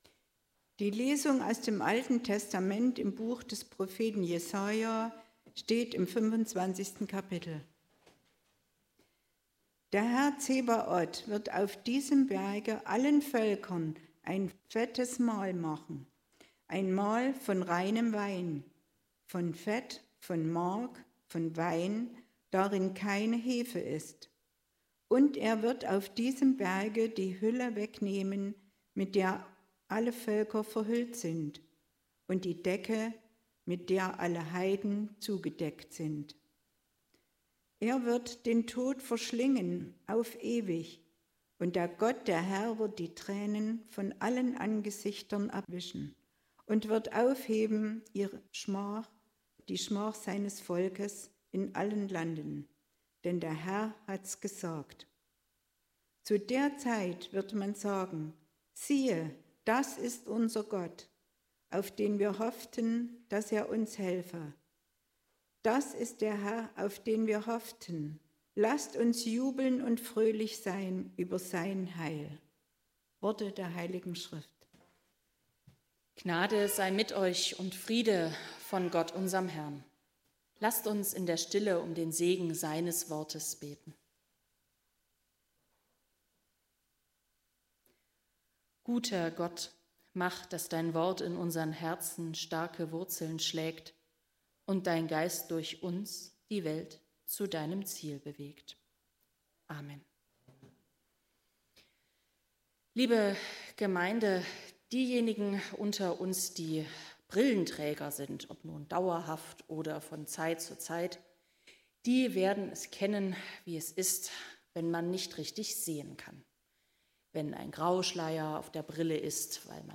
Passage: Jesaja 25 Gottesdienstart: Predigtgottesdienst Obercrinitz « Ostern verändert alles!